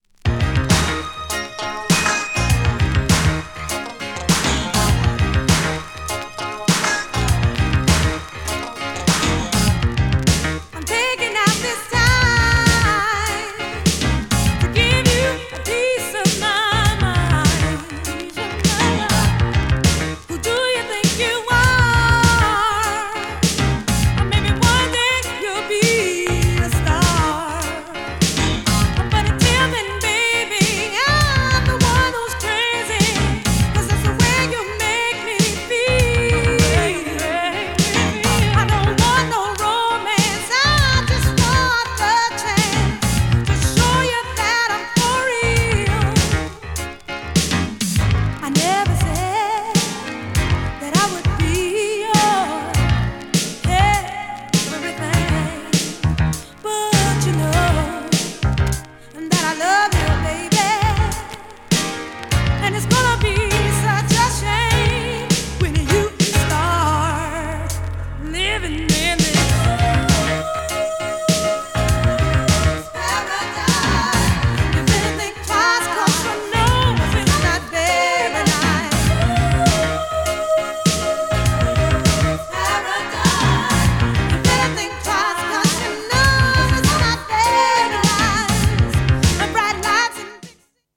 87年リリースのブラコンタイプのダンスチューン。
GENRE Dance Classic
BPM 116〜120BPM